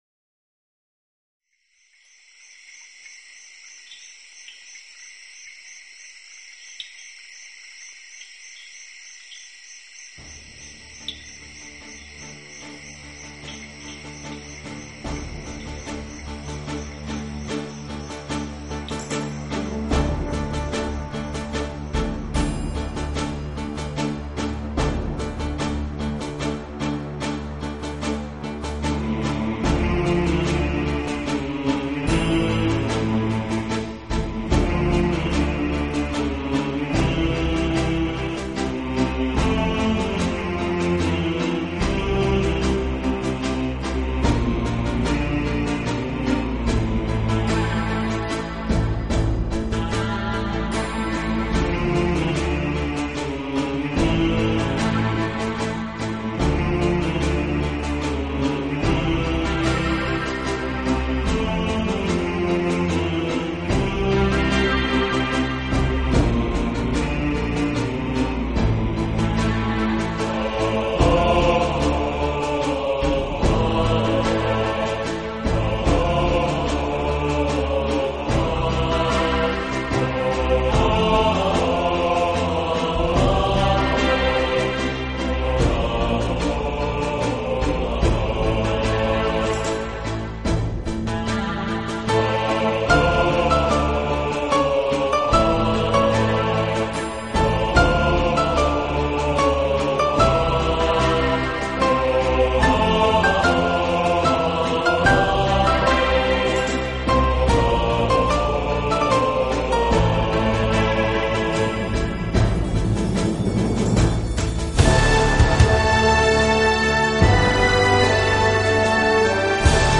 【纯音人声】
兼有慑人心神的旋律。